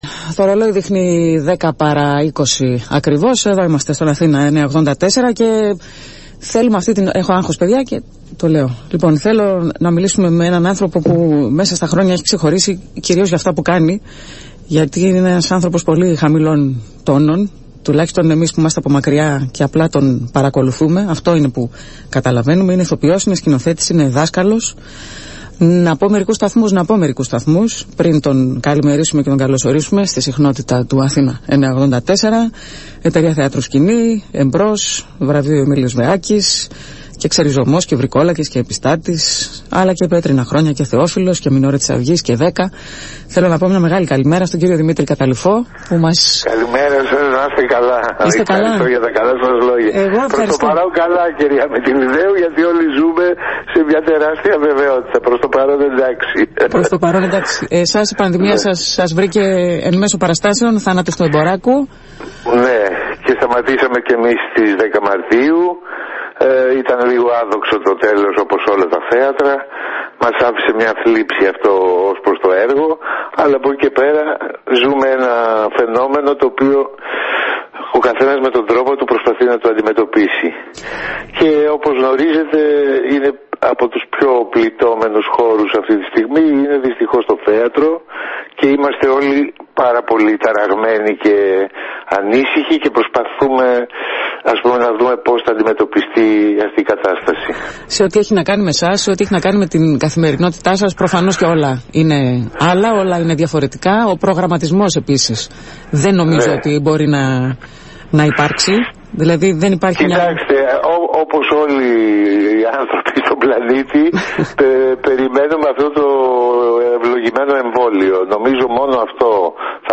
Μίλησε στον Αθήνα 9.84 και στην εκπομπή «ΒΟΛΤΑ»